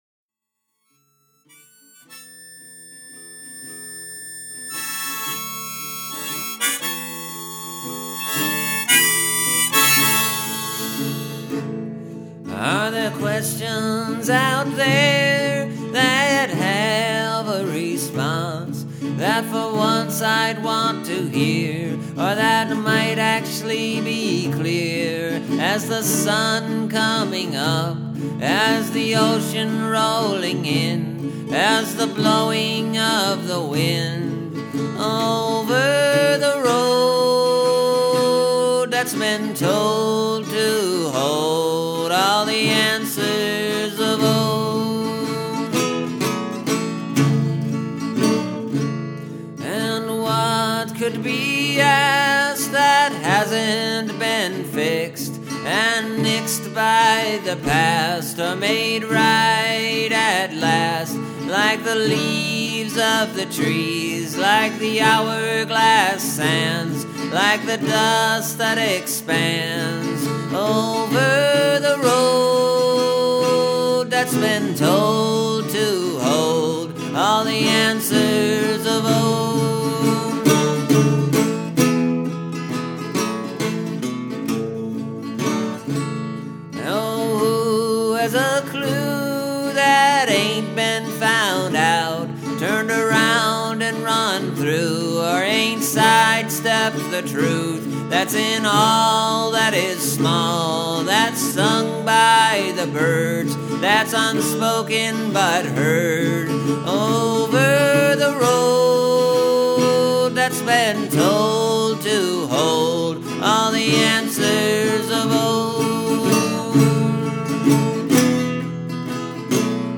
Then the repeating chorus came through.
And this sure is a big ol’ folk song.